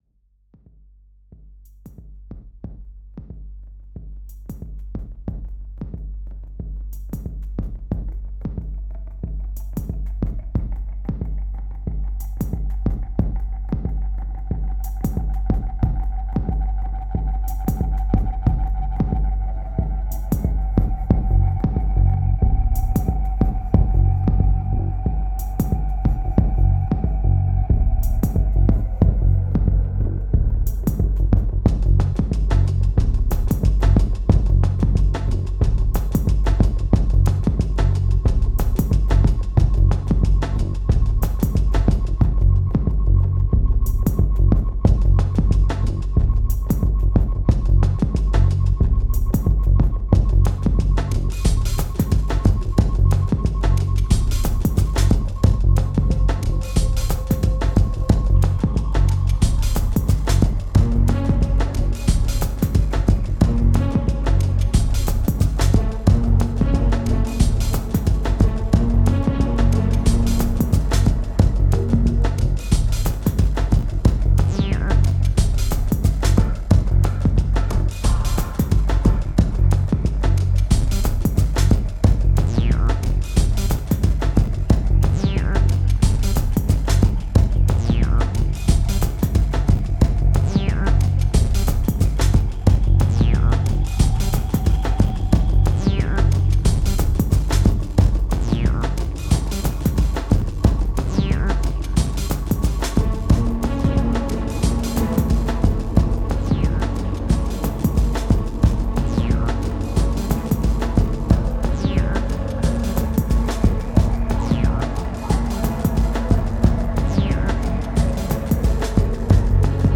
1963📈 - 2%🤔 - 91BPM🔊 - 2010-12-30📅 - -368🌟